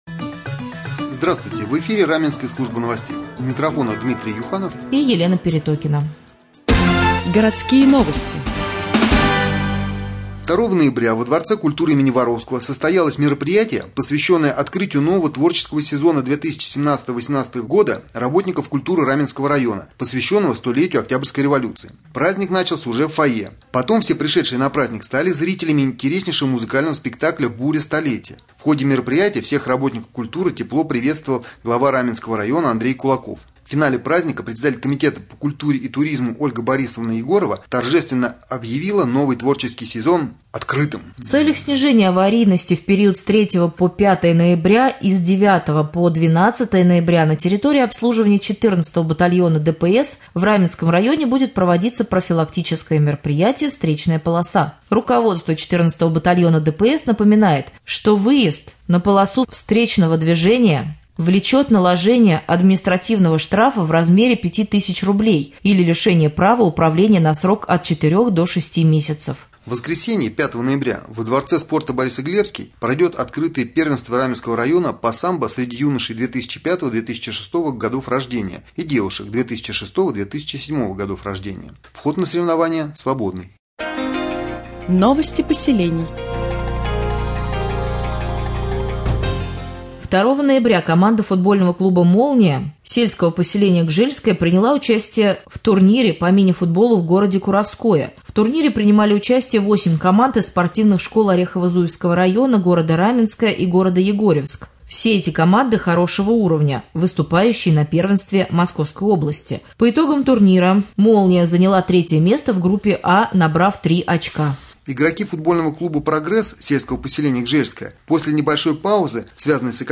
Сегодня в новостном выпуске на Раменском радио Вы узнаете, когда сотрудники ГИБДД проведут в Раменском районе рейды «Встречная полоса», как прошло Открытие творческого сезона Комитета по культуре и туризму 2017-2018гг., какие соревнования пройдут во дворце спорта «Борисоглебский» 5 ноября, а также последние областные новости и новости соседних районов.